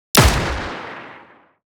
Shotgun.wav